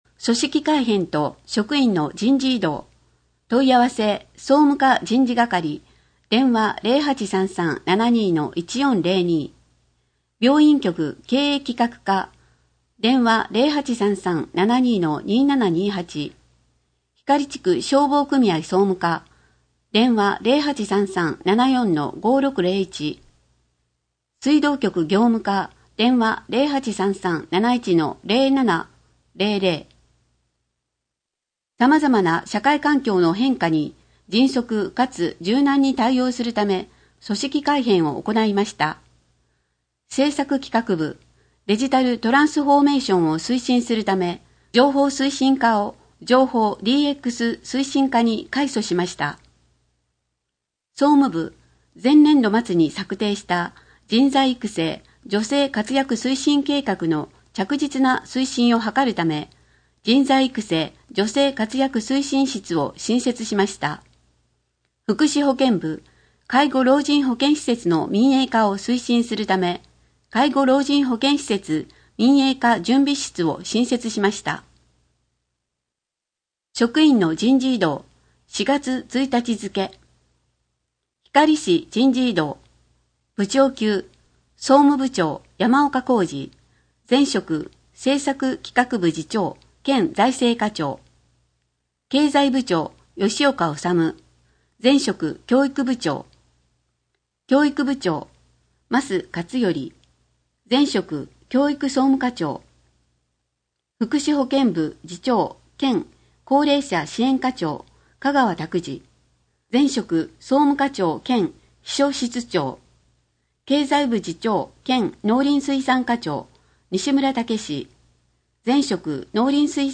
こえの広報 について
広報ひかりでは、 ボランティアグループ「こだまの会」の協力により文字を読むことが困難な視覚障害者や高齢者のために広報紙の内容を音声でもお届けしています。